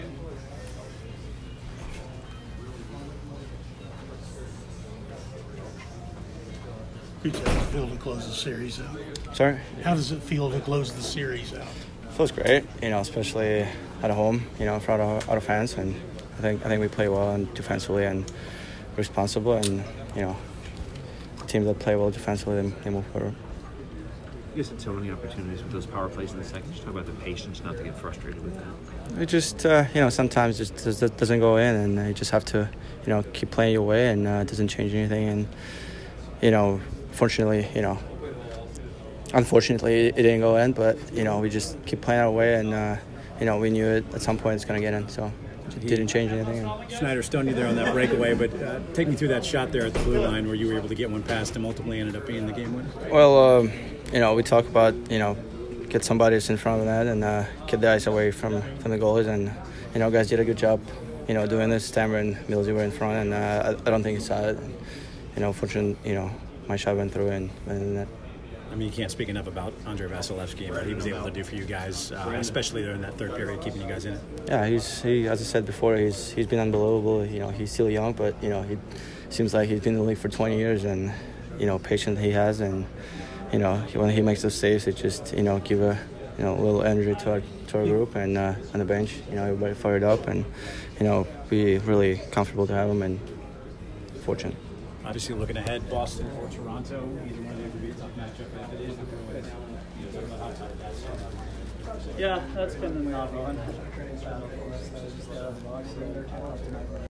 Nikita Kucherov post-game 4/21